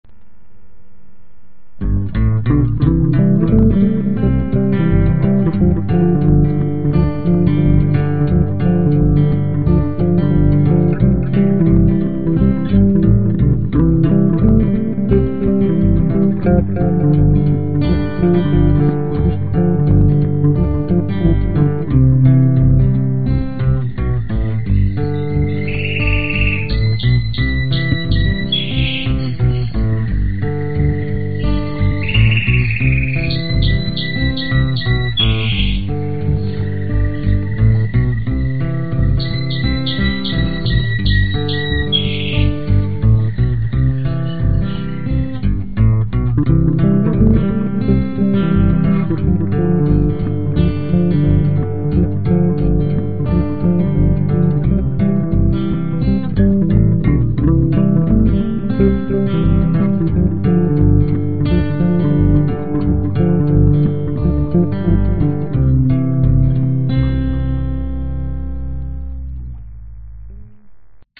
森林环境的中午
描述：12月在西班牙特内里费岛的森林中平静的午间。用Olympus LS12和Rycote挡风玻璃录制。
标签： 现场录音 金丝雀 昆虫 性质 冬季
声道立体声